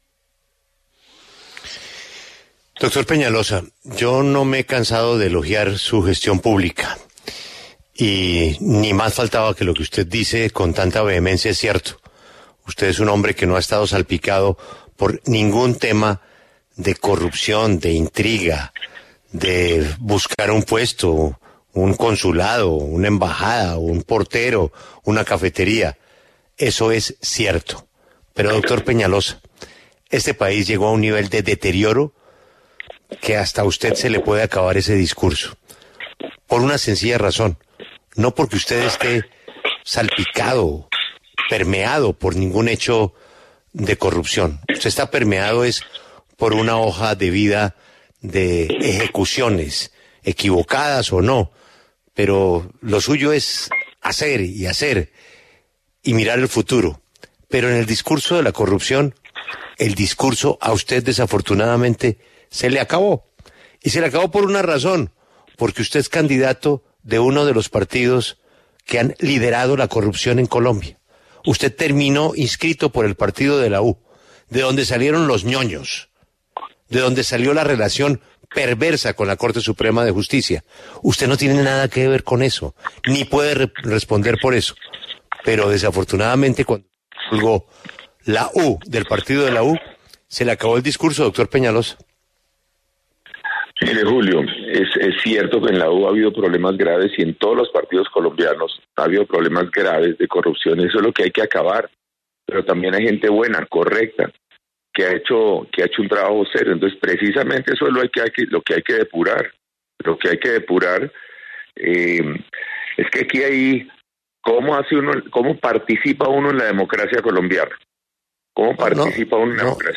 Durante una entrevista que concedió el precandidato presidencial Enrique Peñalosa a W Radio para hablar acerca de la posición de la Coalición de la Experiencia frente a las denuncias que hay contra uno de sus miembros, Alejandro Char, de supuesta corrupción, el director de la emisora, Julio Sánchez Cristo, le hizo una reflexión al exalcalde por su adhesión al Partido de la U y su discurso contra la corrupción.